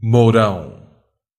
Ääntäminen
Synonyymit pal poteau plumard paddock Ääntäminen France: IPA: /pjø/ Haettu sana löytyi näillä lähdekielillä: ranska Käännös Ääninäyte Substantiivit 1. estaca {f} 2. mourão {m} 3. poste {m} 4. vara {f} Suku: m .